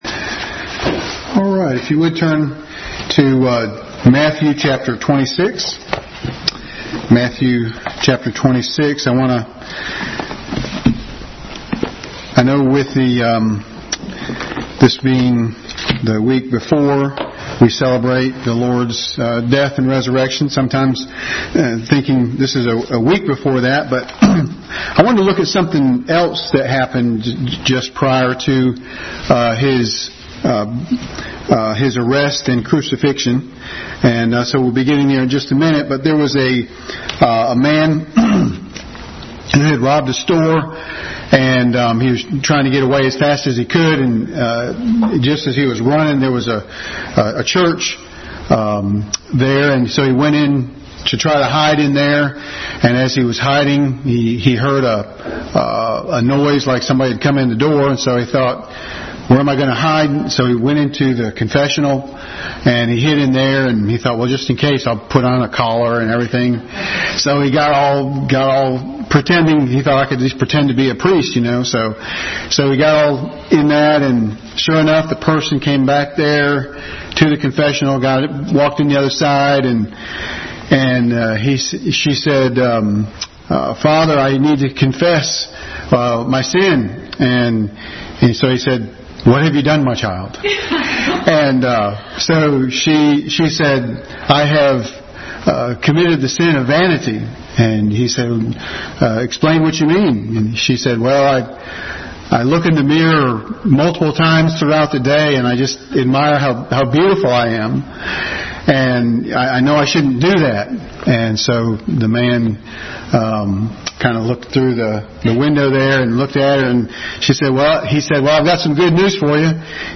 Bible Text: Matthew 26:14-26, Matthew 10:4-8, John 12:26, John 13:18-29, John 12:3-6, John 17:12, Mark 14:21, Matthew 26:47-50, Luke 22:48, Matthew 27:1-5, John 6:66-71 | Family Bible Hour Sermon – Judas Iscariot.